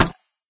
icemetal2.ogg